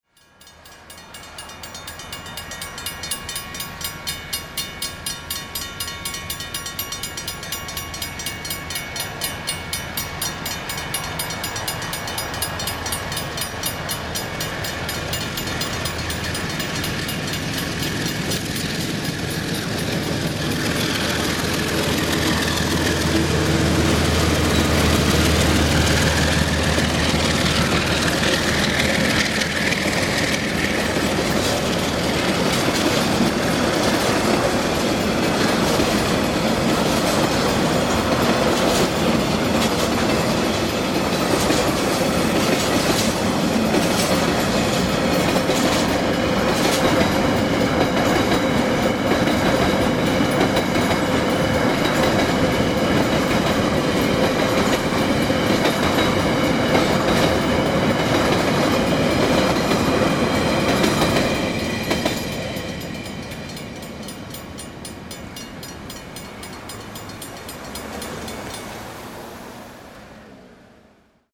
After just catching up to it at Rolleston, where the Midland line branches of from the line south, I noticed another headlight in the distance, so waited near the level crossing to see a double headed DX powered empty coalie taking the junction towards Greymouth.